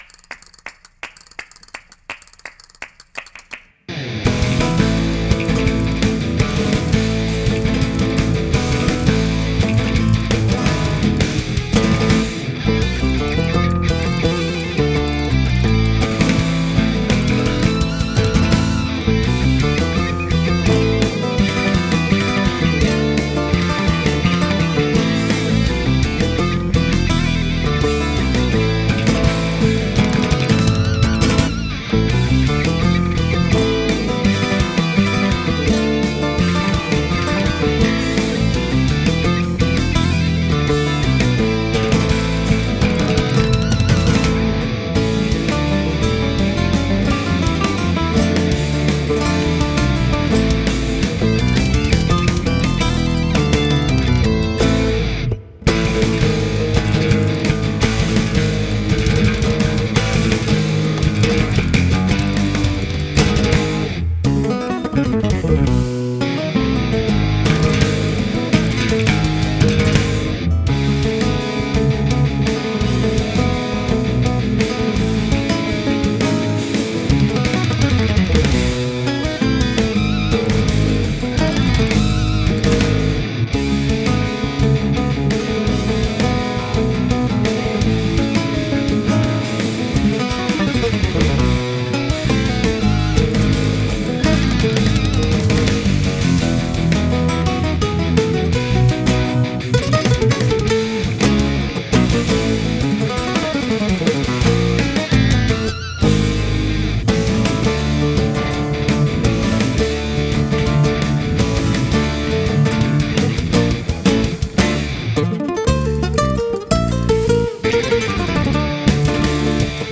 Genre: Guitar Virtuoso, Speed Metal, Flamenco